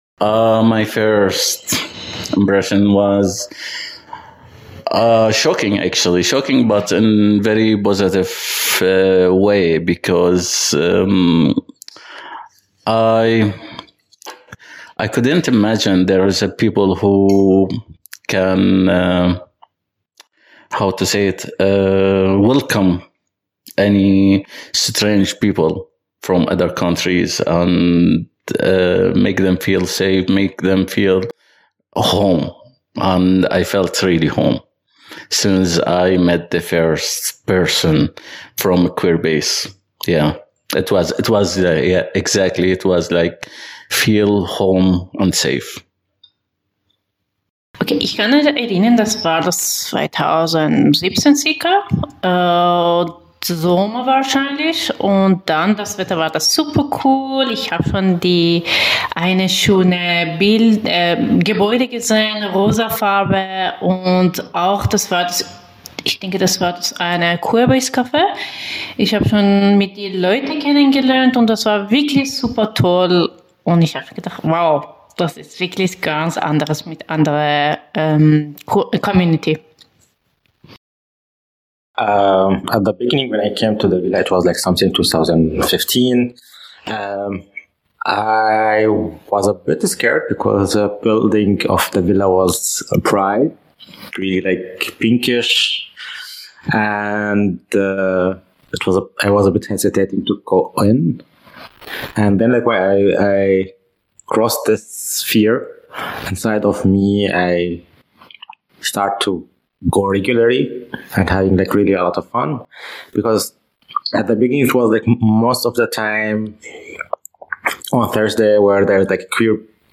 Oral History: Die Geschichte queerer Migration und Flucht ist geprägt von dem Streben nach Freiheit und Selbstbestimmung.